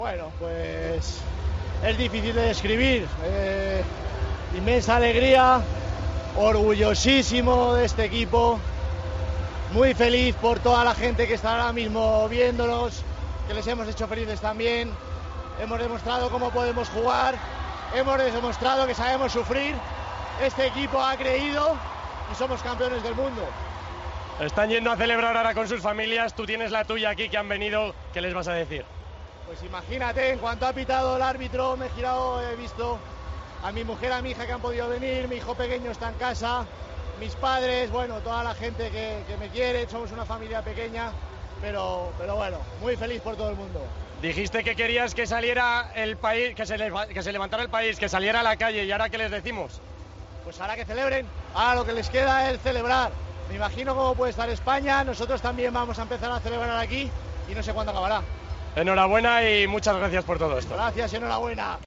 El seleccionador nacional habló en TVE nada más proclamarse campeona del Mundo después de ganar 1-0 a Inglaterra.